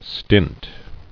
[stint]